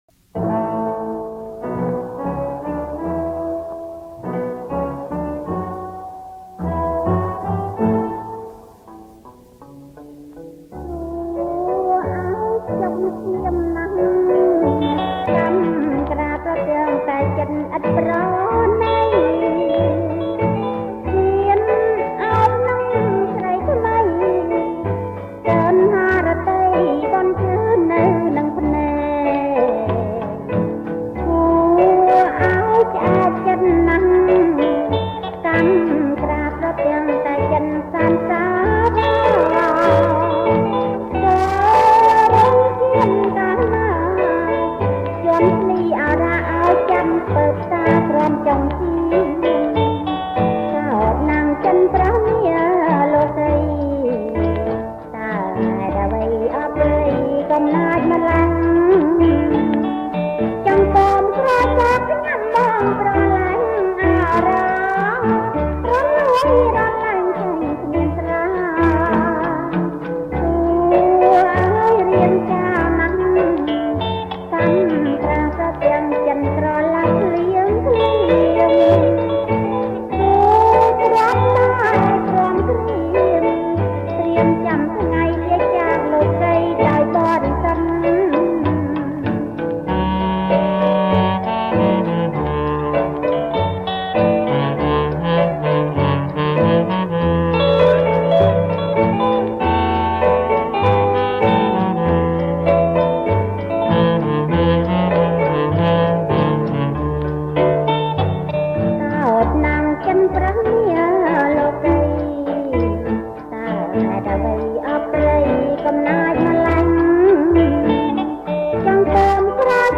• ប្រគំជាចង្វាក់ Bolero Twist